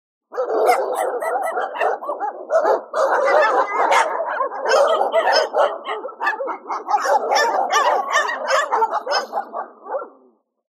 数頭の犬が「わんわん わんわんわんわん」と鳴きます。
数頭の犬の鳴き声02 着信音